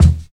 88 KICK.wav